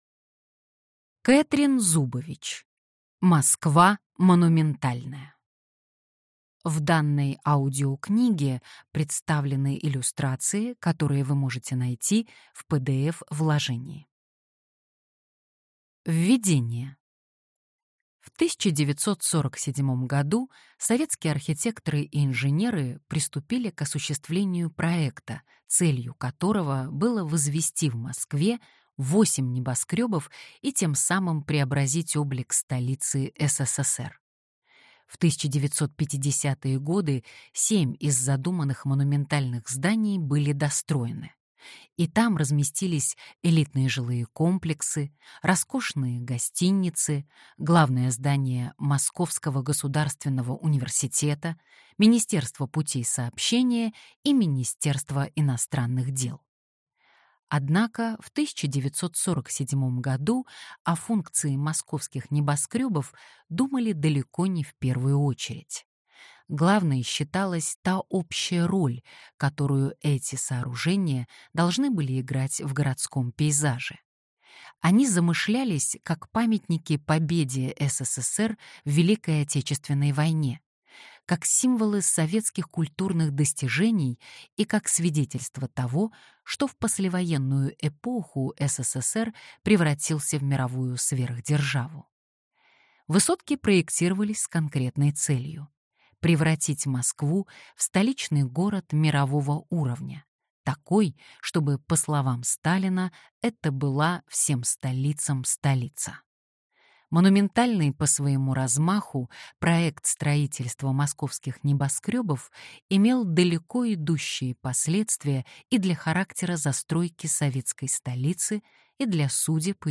Аудиокнига Москва монументальная. Высотки и городская жизнь в эпоху сталинизма | Библиотека аудиокниг